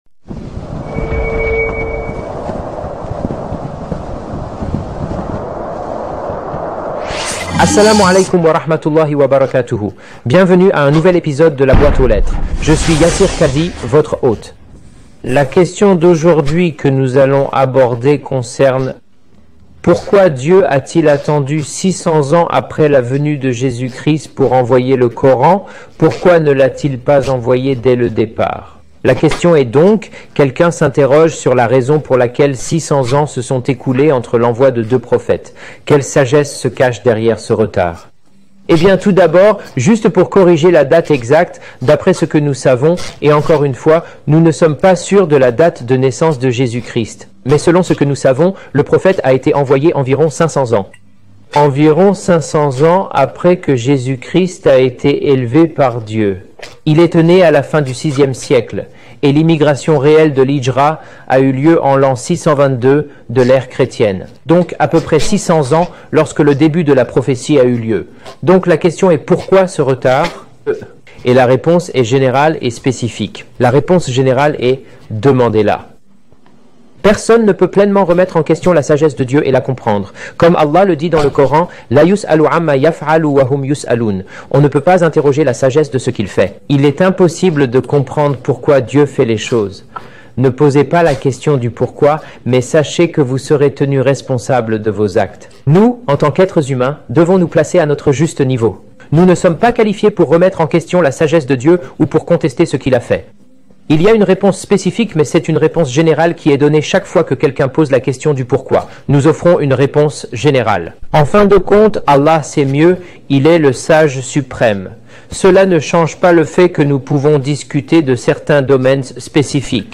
Description: Dans cet épisode de "Mailbag", Yasir Qadhi répond à la question : "Pourquoi Dieu a-t-il attendu 600 ans après Jésus pour révéler le Coran ?"